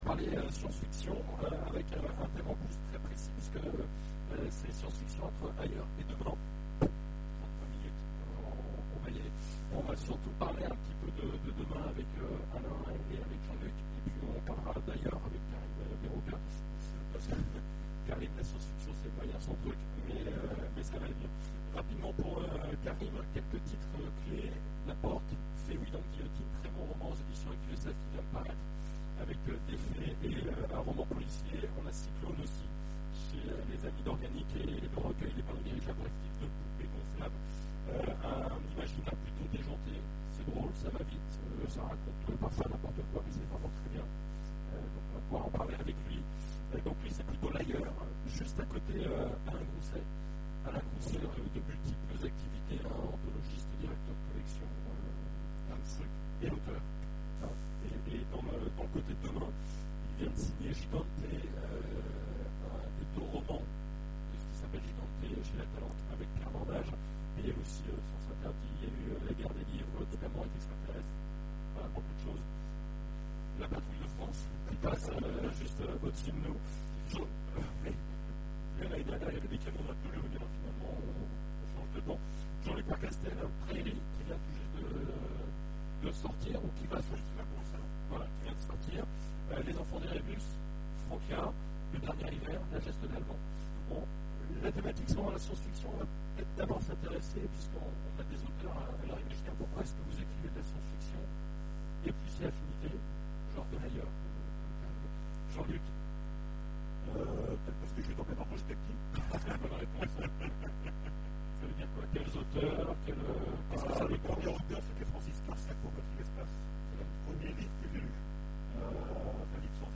Imaginales 2014 : Conférence La science-fiction